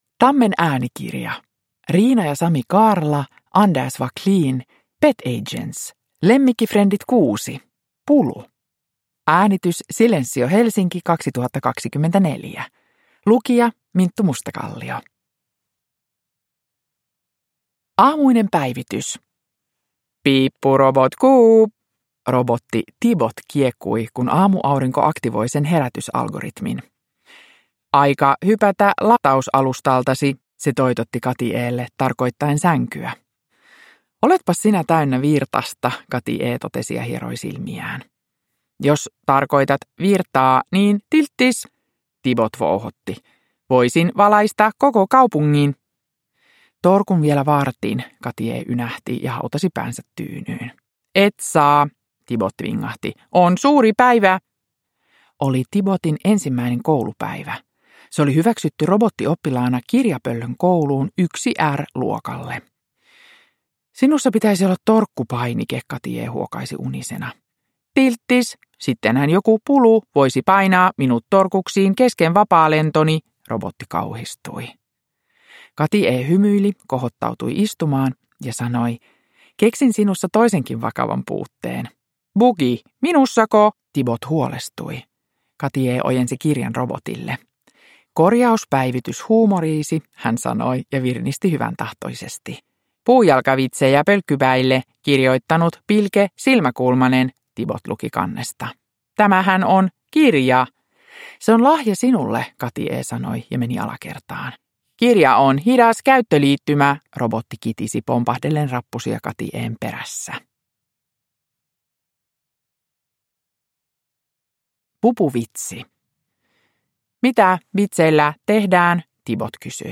Pulu. Lemmikkifrendit 6 – Ljudbok
Uppläsare: Minttu Mustakallio